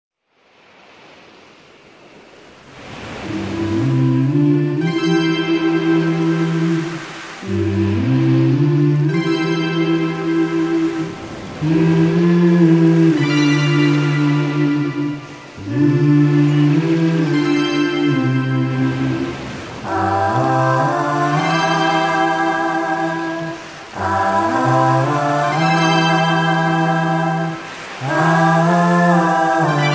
Tags: rock , top 40 , singers , sound tracks